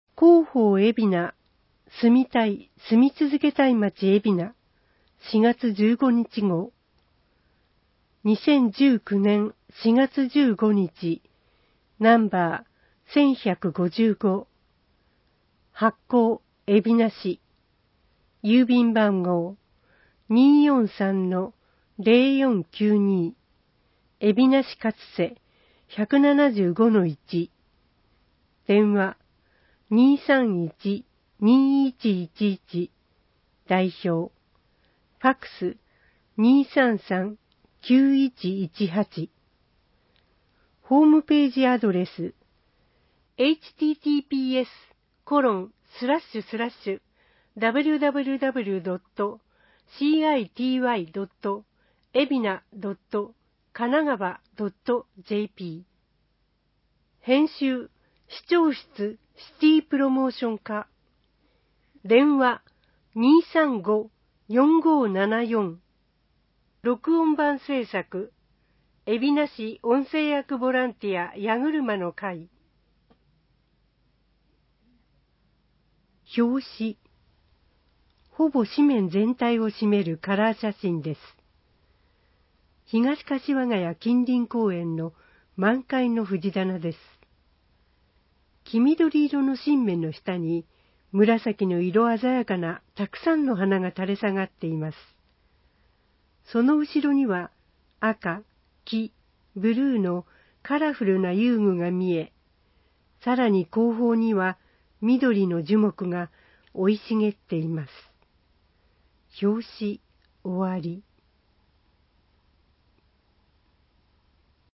広報えびな 平成31年4月15日号（電子ブック） （外部リンク） PDF・音声版 ※音声版は、音声訳ボランティア「矢ぐるまの会」の協力により、同会が視覚障がい者の方のために作成したものを登載しています。